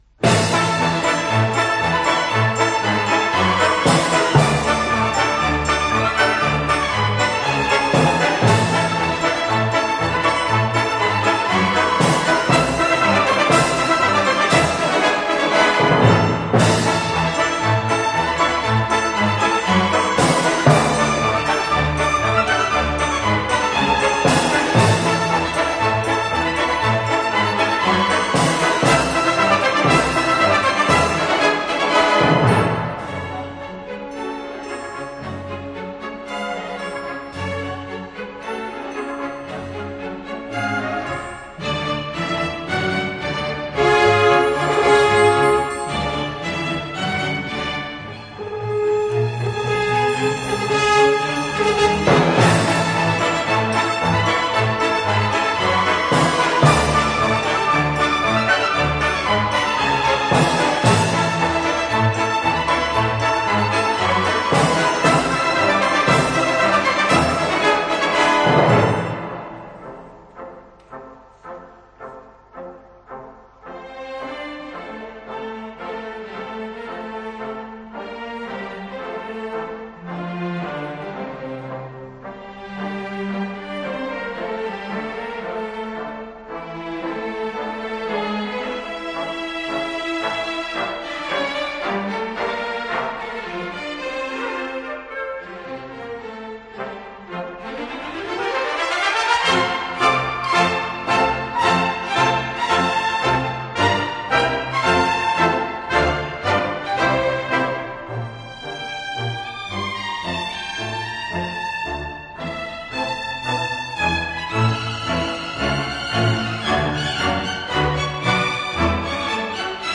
Жанр: Easy Listening